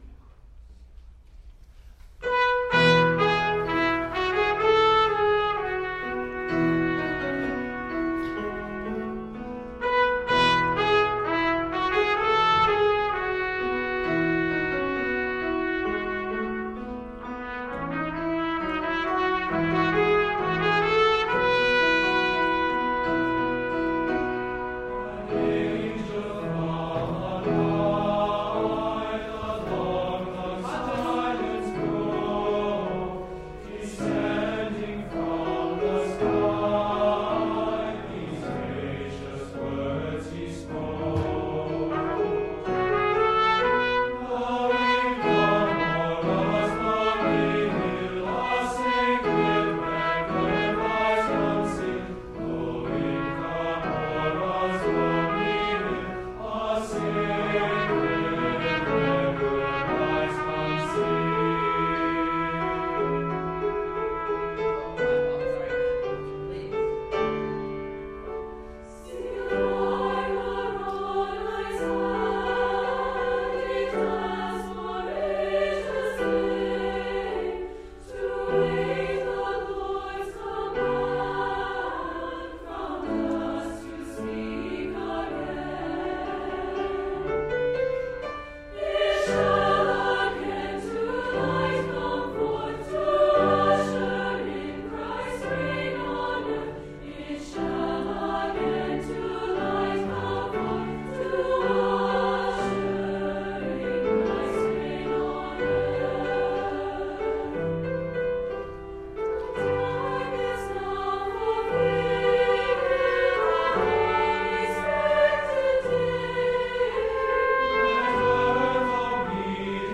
SATB Choir. Trumpet, and Piano
Hymn arrangement